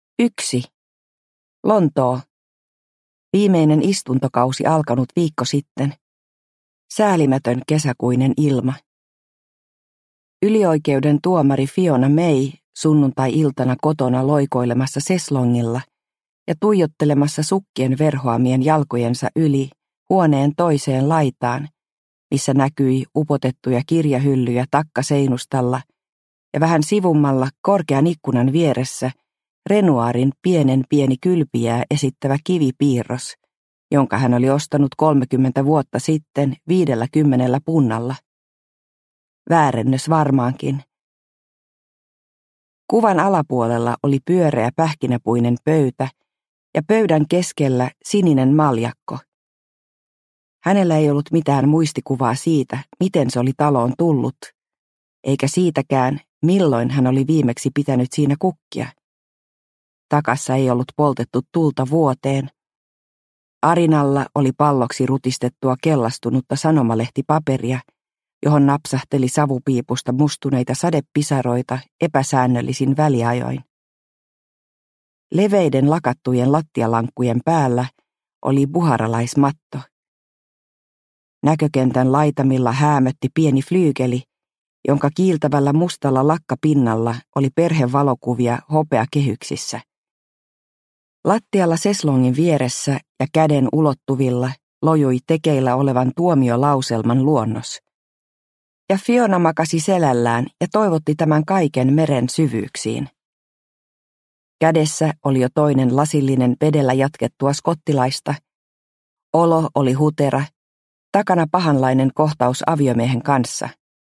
Lapsen oikeus – Ljudbok – Laddas ner